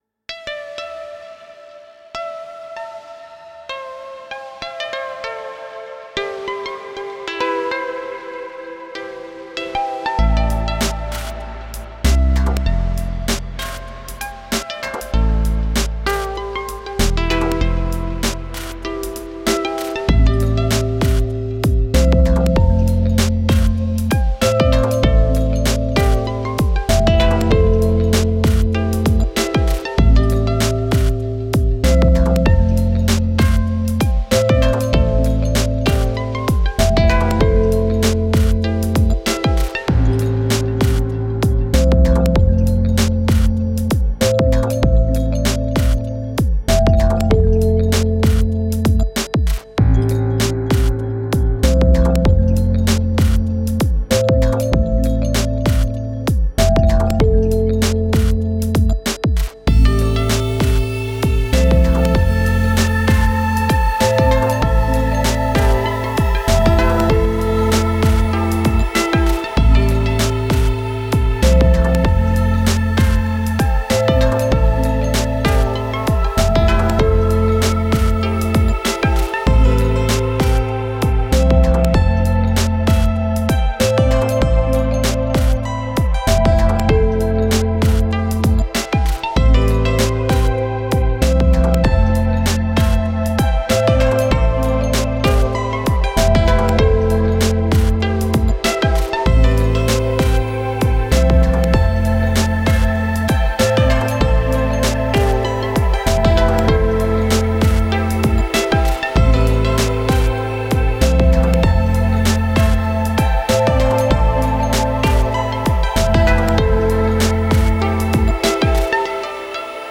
A small lofi sketch I made while on the train (M8 with 1 track (pad) from the Virus - starts playing from 1st minute) with a light touch of Strymon BigSky and Timeline: